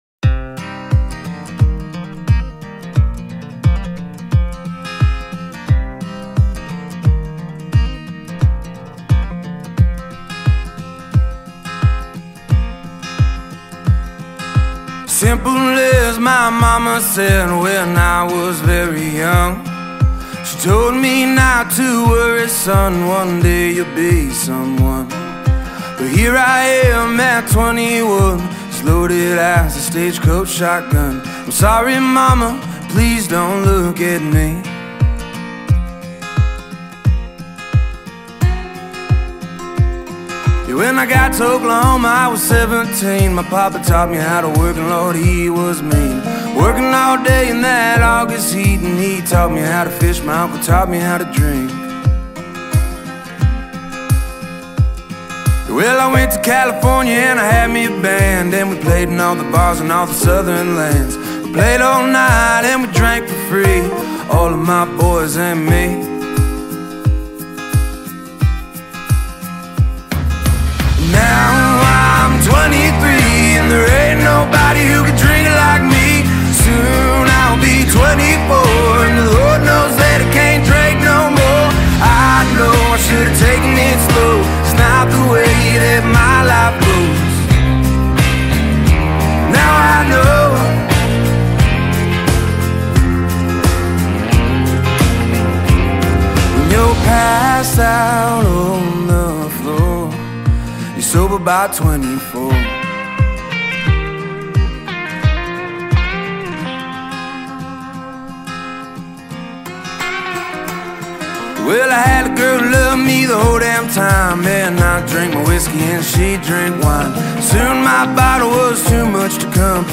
I’m not a big fan of country